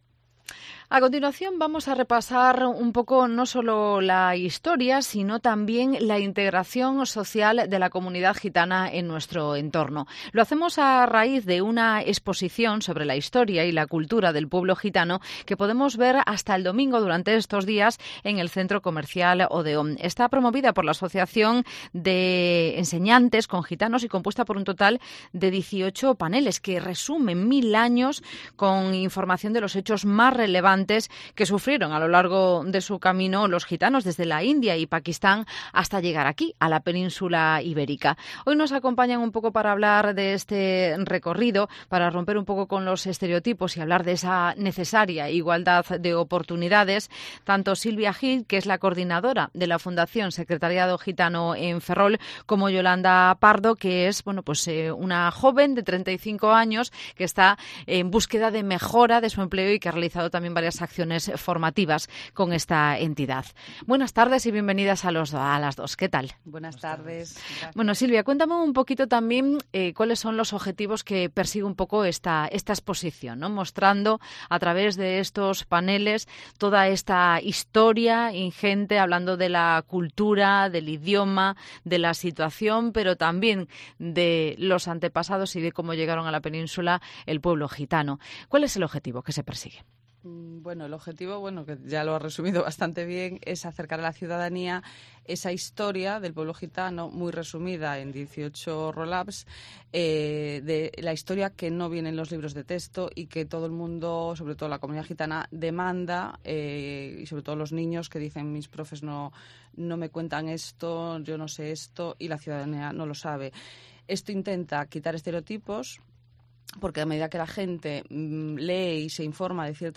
en los estudios de COPE Ferrol